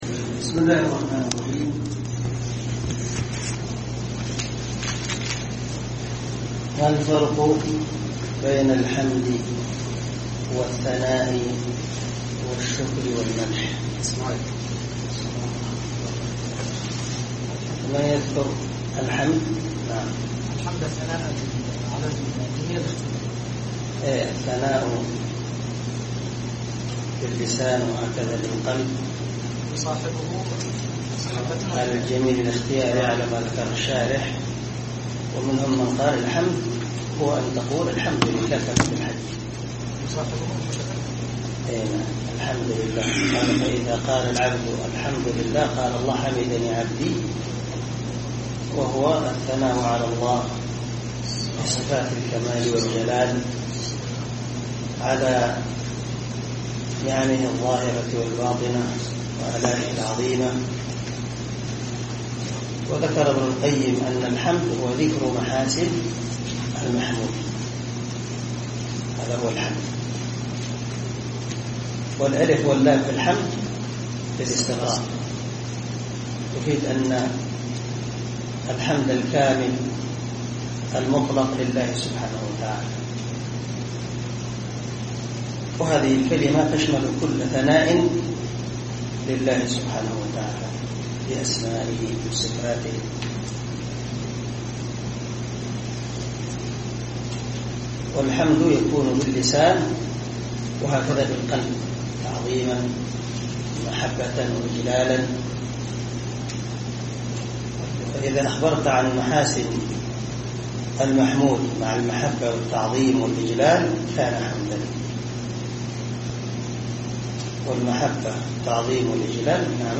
عنوان الدرس: الدرس الرابع
دار الحديث- المَحاوِلة- الصبيحة.